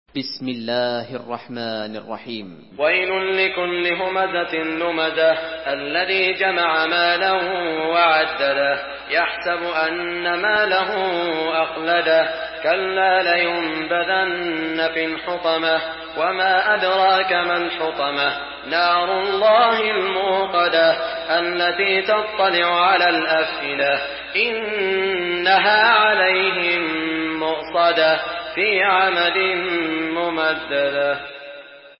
Sourate Al-Humaza MP3 à la voix de Saud Al Shuraim par la narration Hafs
Une récitation touchante et belle des versets coraniques par la narration Hafs An Asim.
Murattal Hafs An Asim